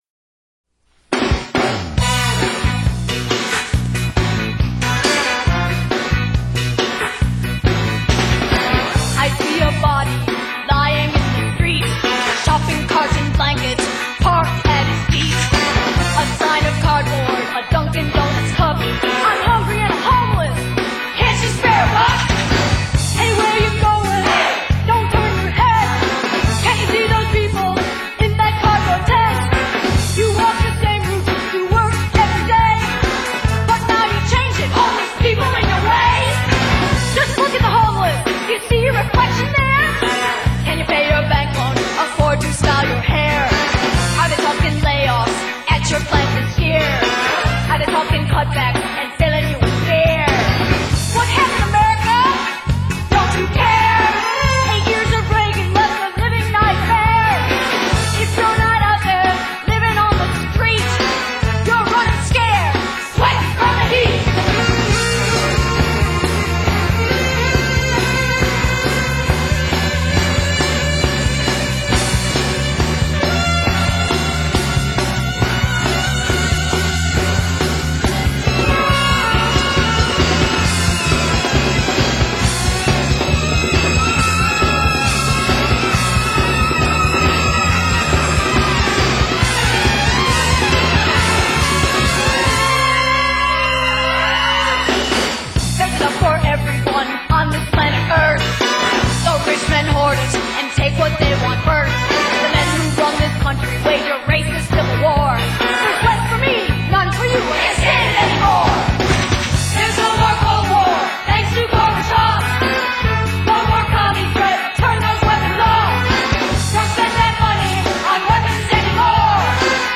lo-fi
Sung by the writers except as noted.